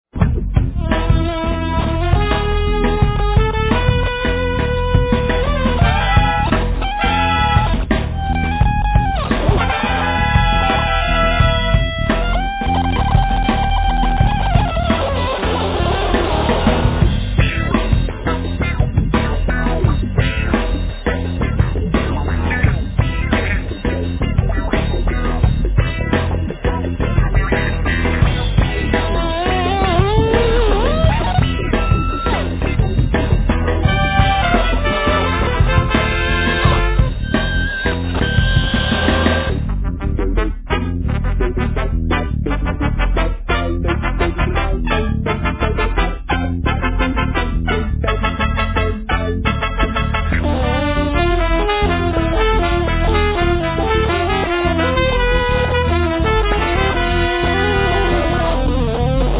とにかく曲、サウンドのセンスの良さと、パワフルながらも余裕さえ感じる落ち着いた演奏には、脱帽。